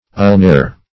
Ulnare \Ul*na"re\, n.; pl. Ulnaria. [NL. See Ulna.] (Anat.)